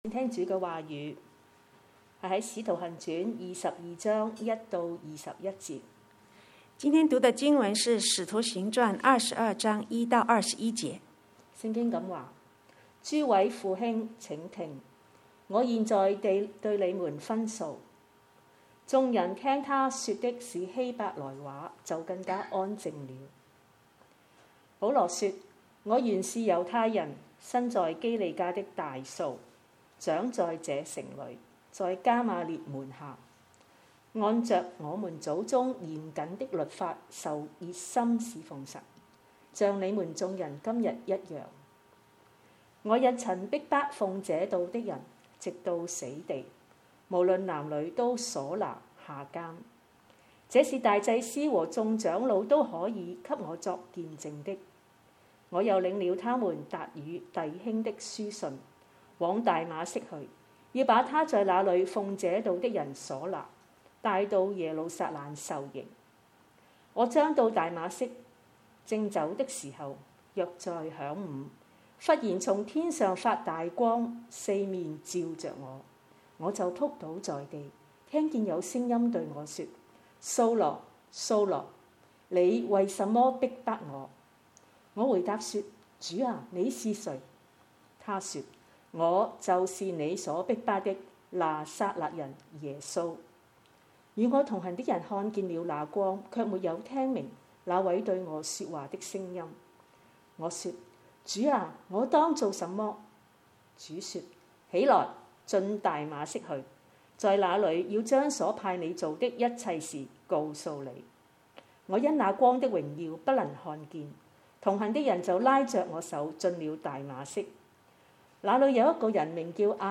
2022 講道錄音